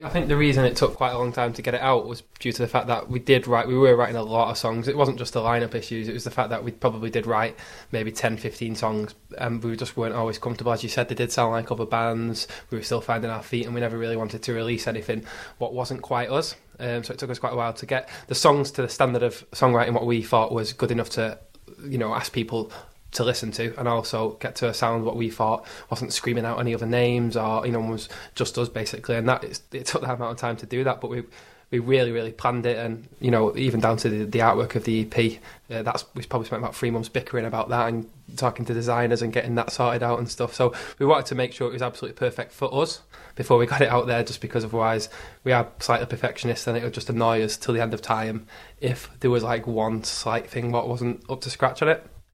Project Aura Interview